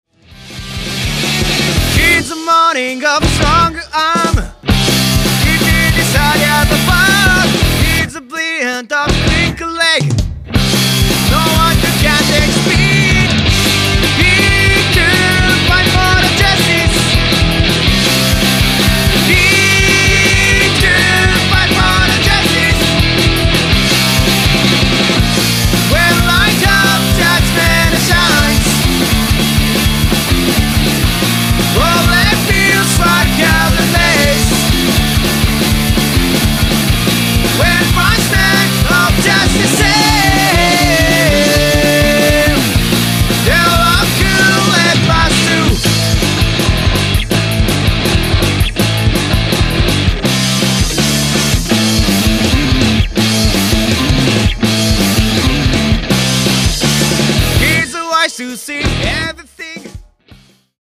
Guitar
Bass
Drums & Percussion